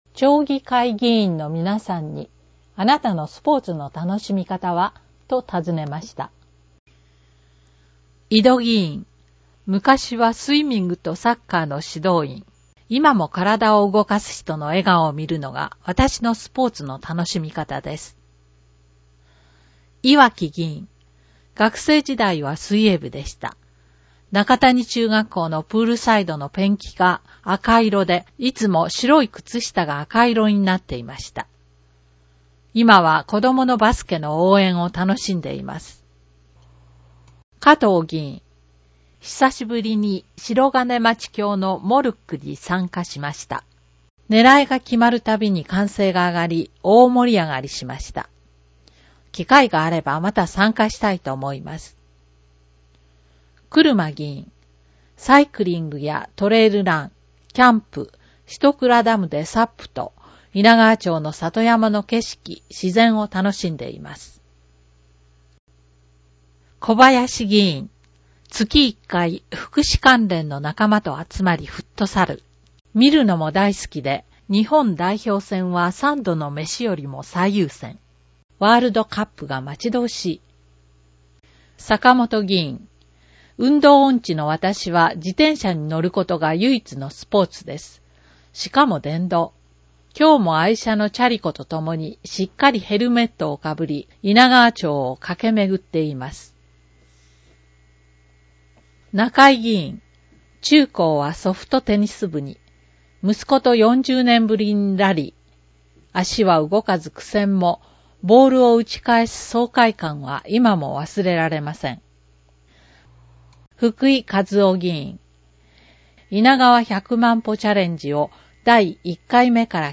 制作は猪名川町社会福祉協議会 音訳ボランティア リヴィエールの方々の協力によるものです。